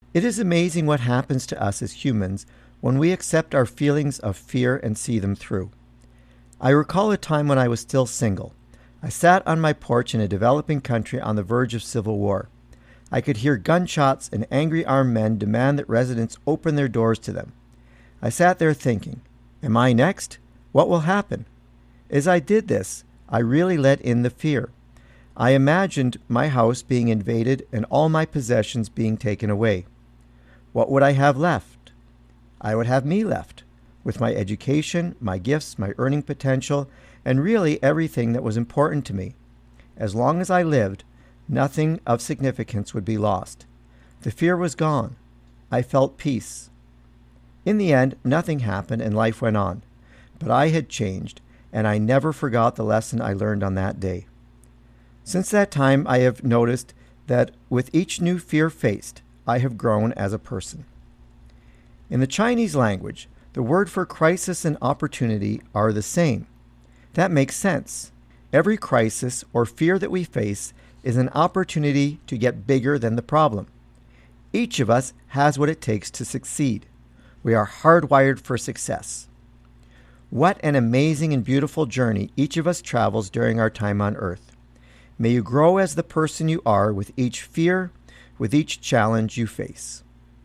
Type: Commentary
224kbps Stereo